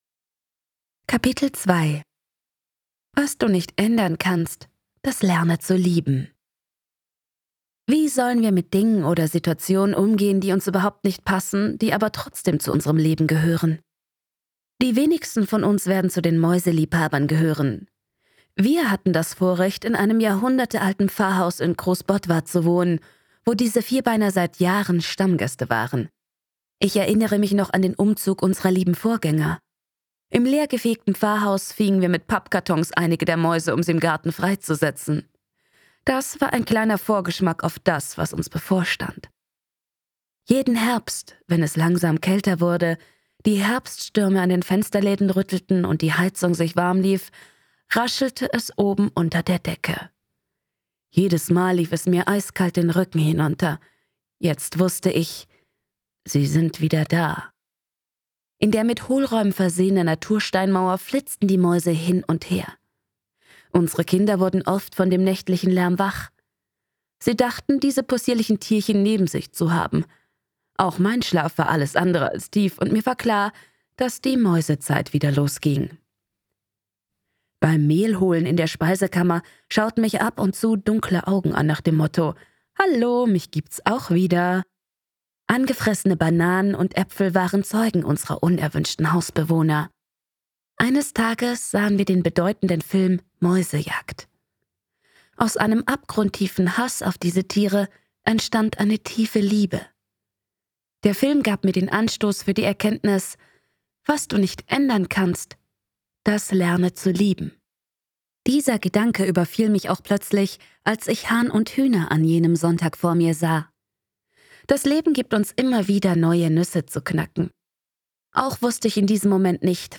Zwischen Huhn und Himmel - Hörbuch